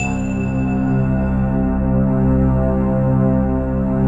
SI1 BELLS02L.wav